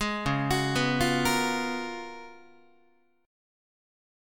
C#13 chord